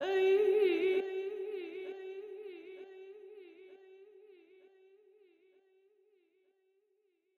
Vox
TLC - Servant (Chant).wav